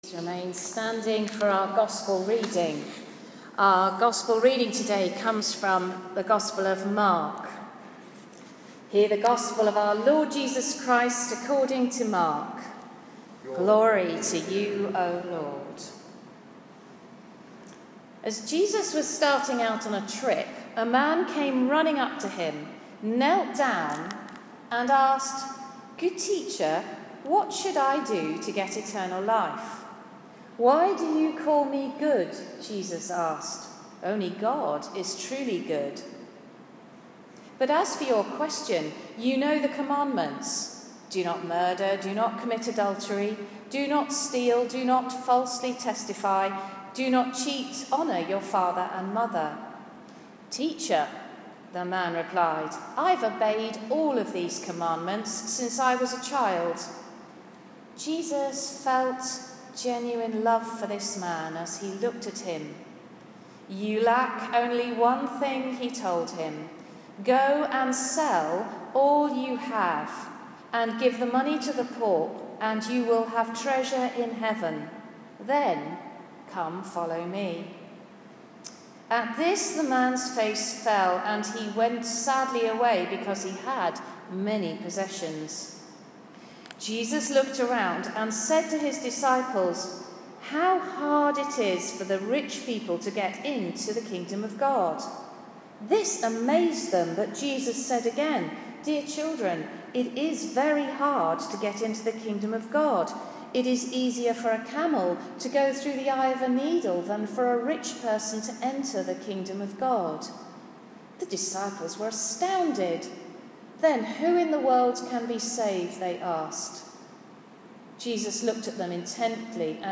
Below is a link to the sermon this morning at St Peter and St John. I was speaking about how to keep on praying when we are tempted to give up.
sermon-11-oct1.m4a